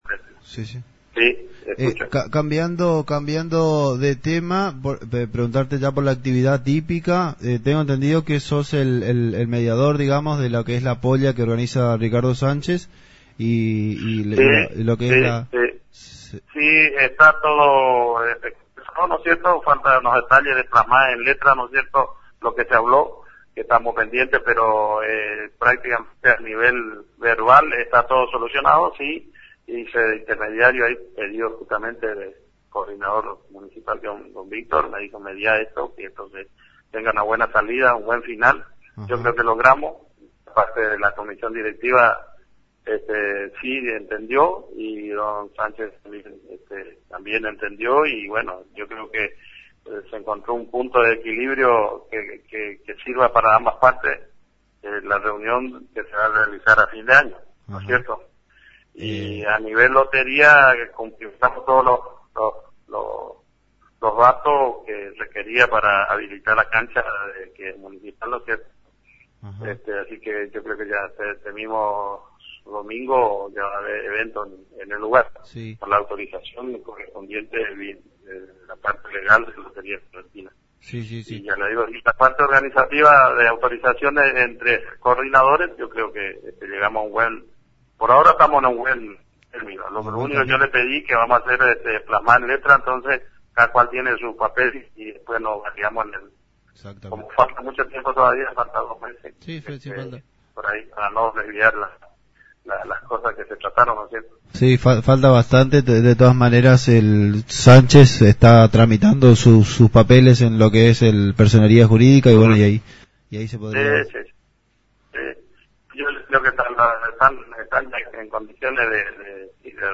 Entrevistas
Entrevista en Radio Chart Mercedes 89.1